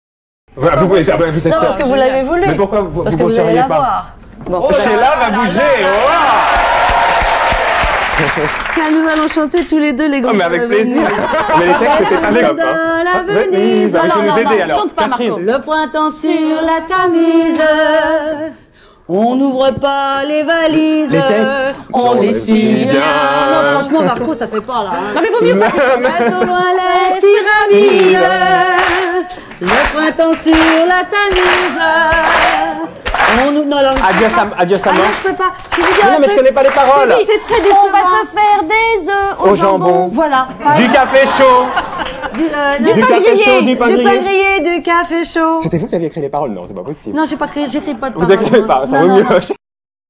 Duo Fogiel - Sheila (surtout Sheila !!!) =